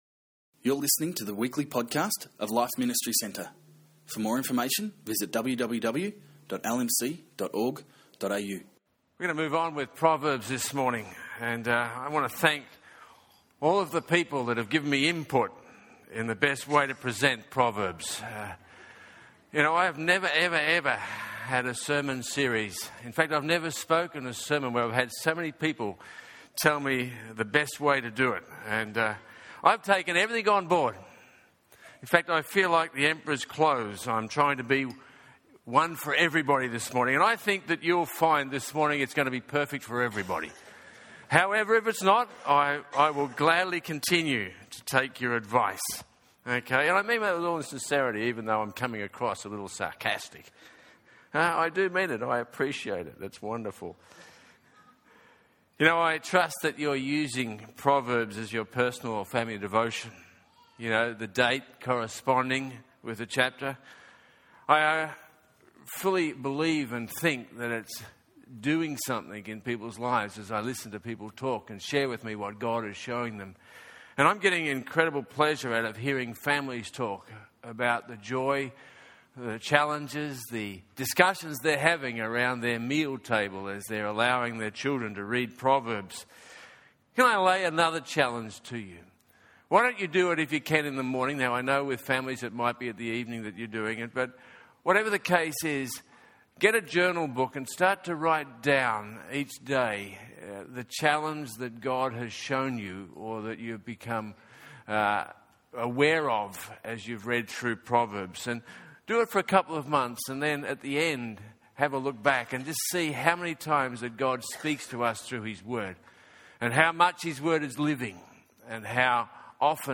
This message focuses on sexual purity.